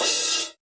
Index of /90_sSampleCDs/Roland - Rhythm Section/CYM_Crashes 1/CYM_Crsh Modules